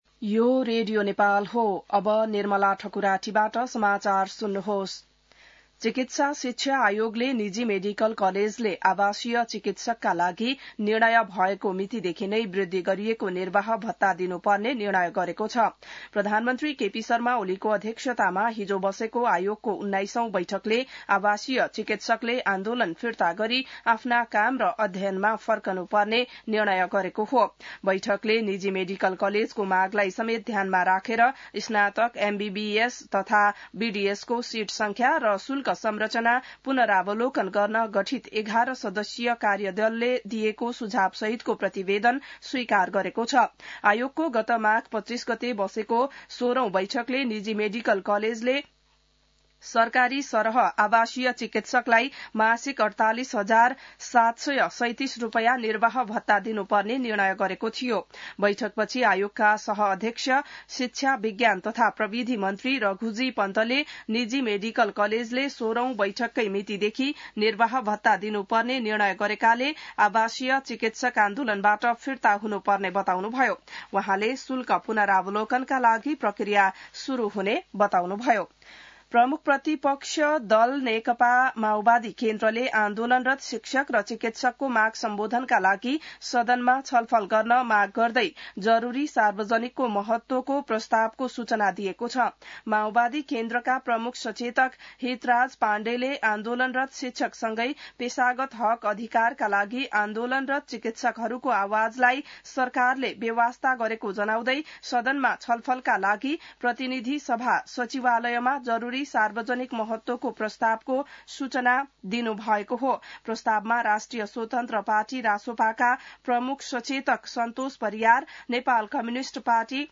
An online outlet of Nepal's national radio broadcaster
बिहान ६ बजेको नेपाली समाचार : १६ वैशाख , २०८२